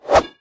fire3.ogg